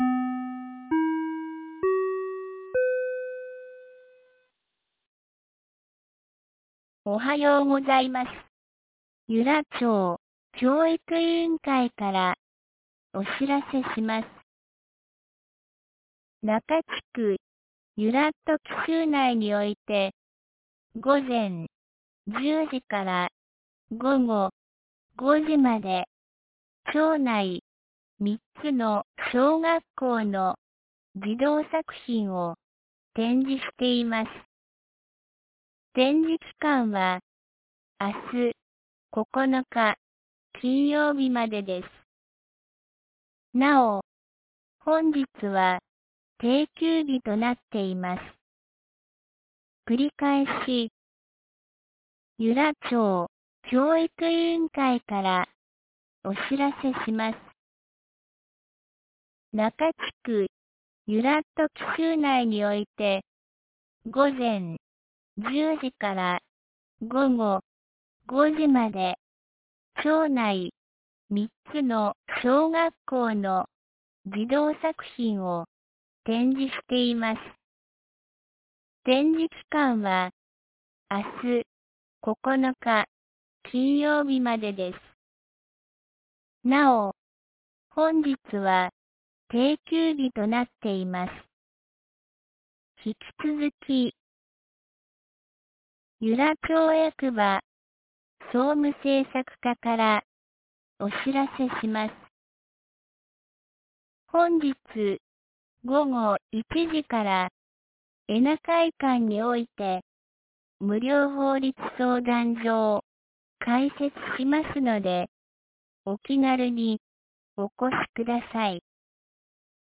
2019年08月08日 07時52分に、由良町より全地区へ放送がありました。